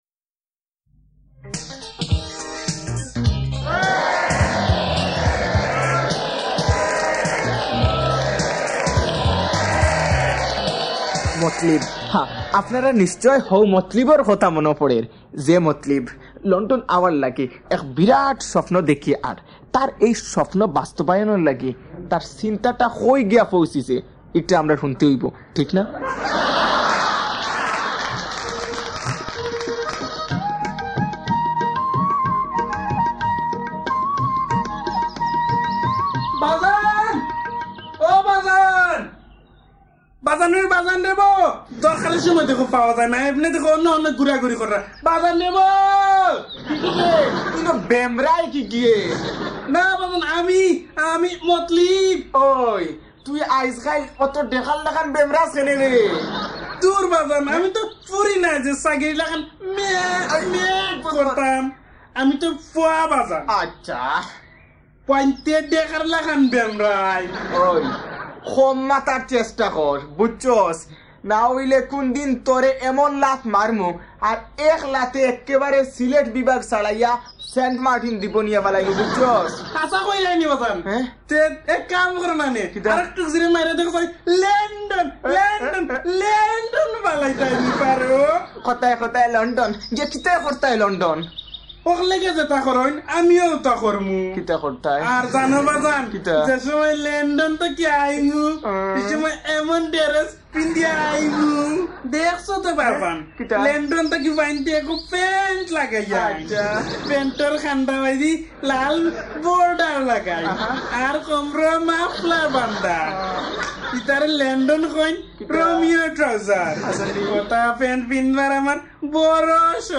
Jhala Part 2 – Comedy